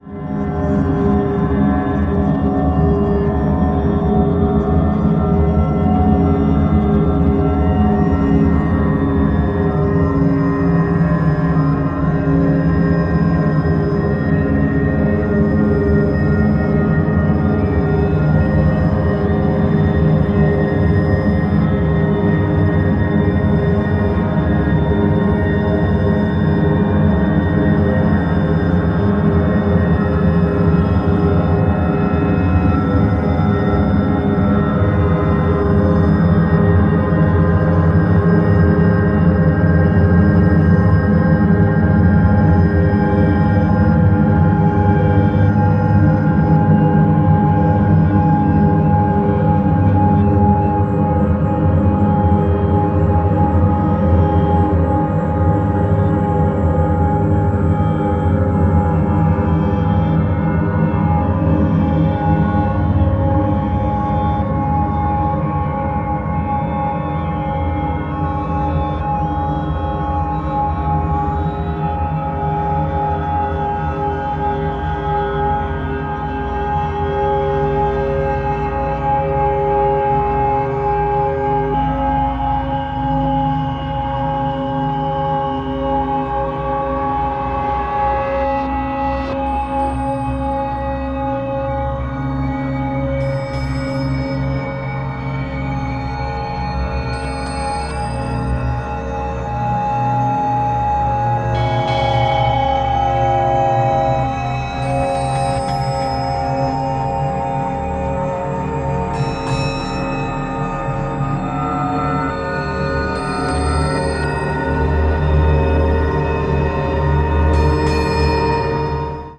超深淵な持続音!!
a brand new single long drone/tape loop piece